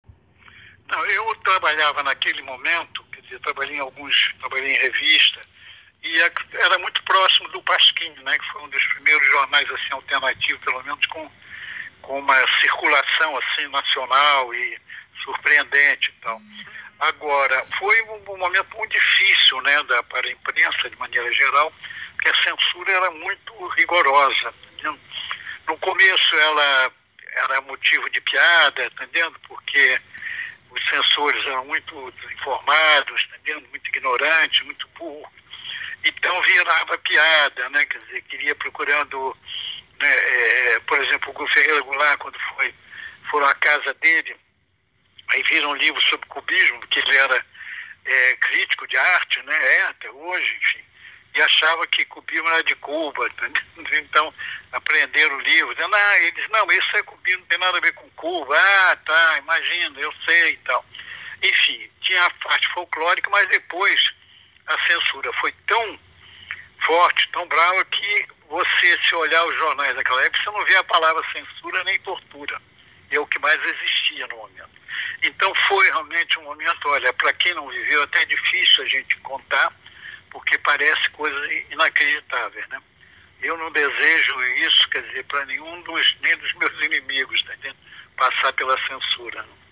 DONC entrevista Zuenir Ventura